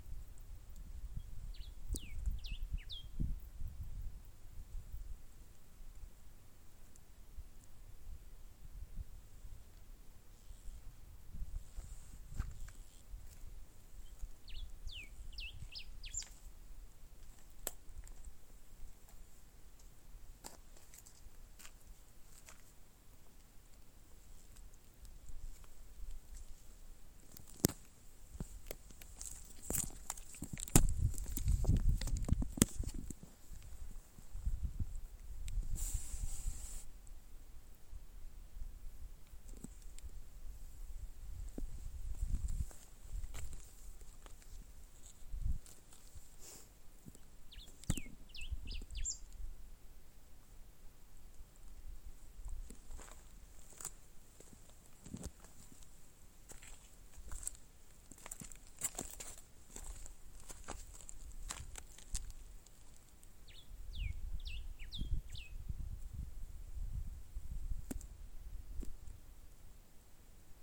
Diuca Común (Diuca diuca)
Zona Encalilla
Nombre en inglés: Diuca Finch
Localidad o área protegida: Amaicha del Valle
Condición: Silvestre
Certeza: Vocalización Grabada